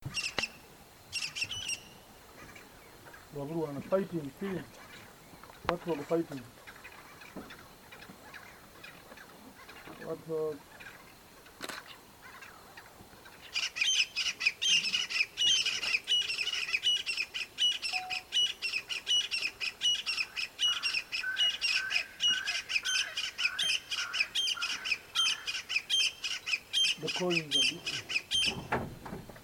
Francolín Capirotado (Ortygornis sephaena)
150908_004-Crested-Francolin.mp3
Condición: Silvestre
Certeza: Fotografiada, Vocalización Grabada